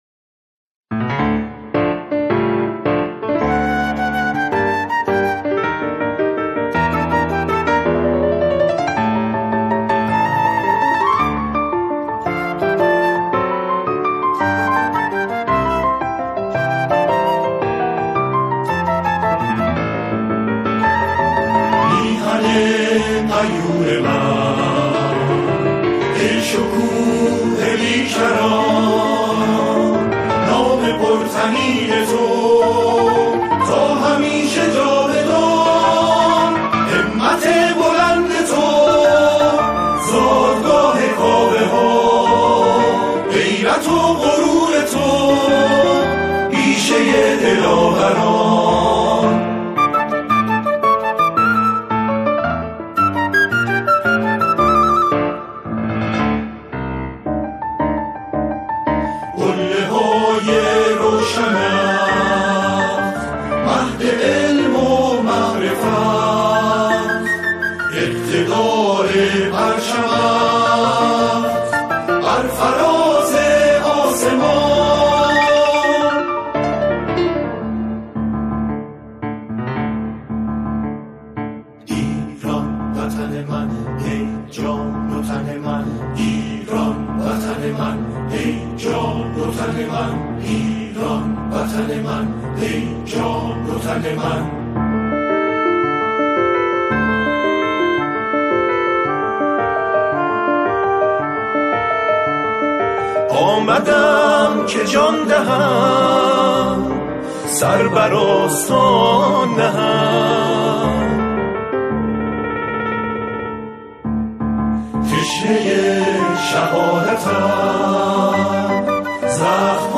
با اجرای گروهی از جمعخوانان